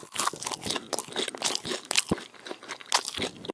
sheep-eat.ogg